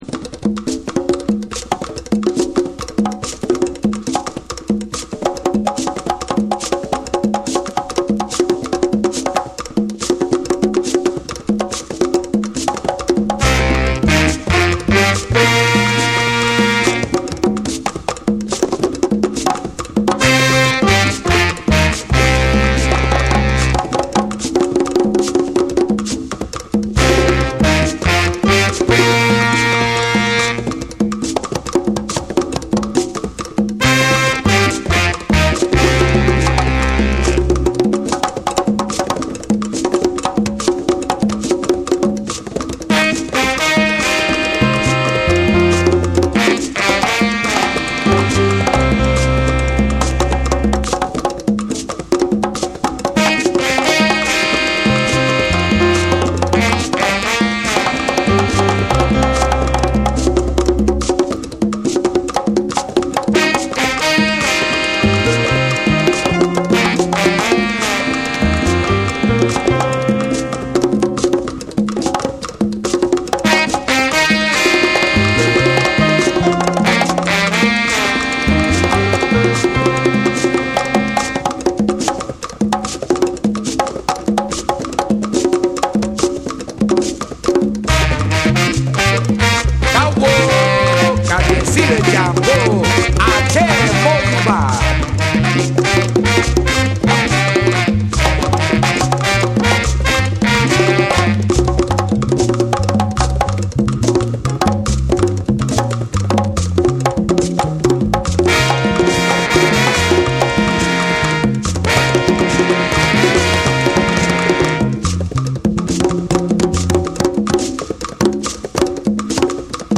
躍動感あふれるパーカッションのリズムが炸裂する
WORLD